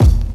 Tuned drums (C key) Free sound effects and audio clips
• Low Bass Drum Sound C Key 344.wav
Royality free kick single shot tuned to the C note. Loudest frequency: 224Hz
low-bass-drum-sound-c-key-344-kTX.wav